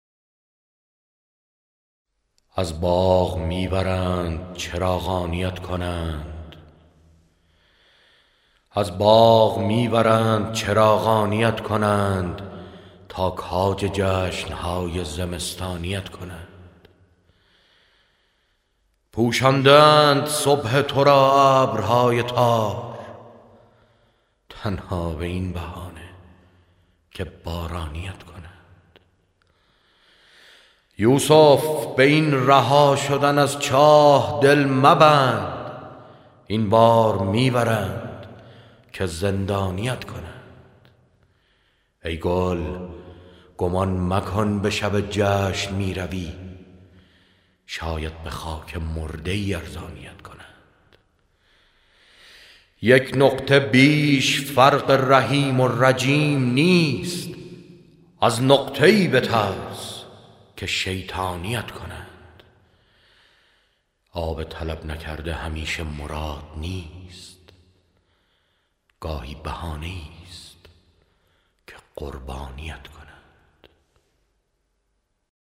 فاضل نظری             دکلمه شعر